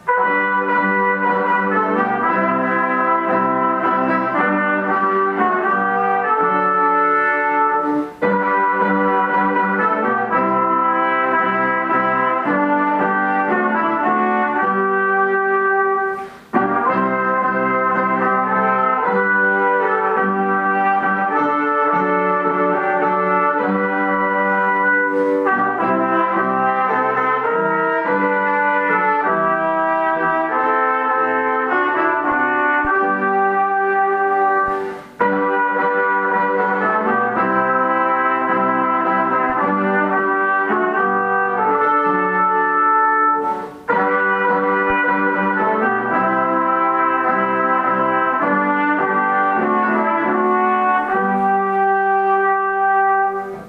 Trois jeunes chrétiens ont à cœur de jouer des cantiques à la trompette.